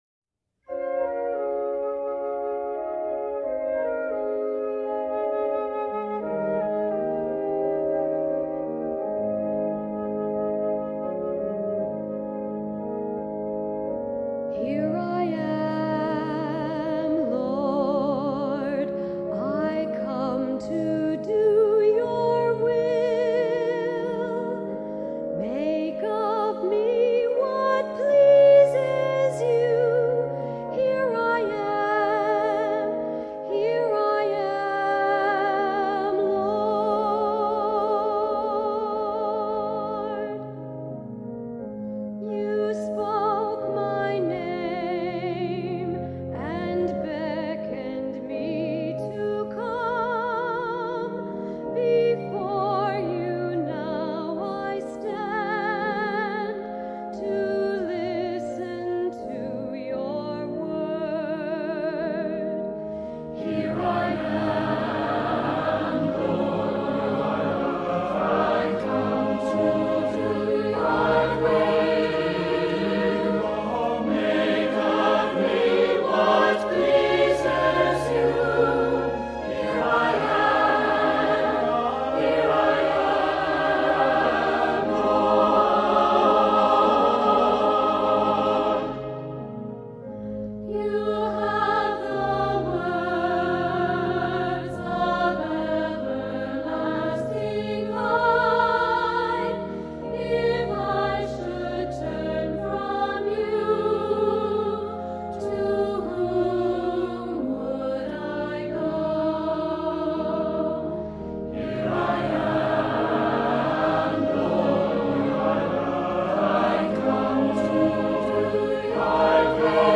Voicing: Cantor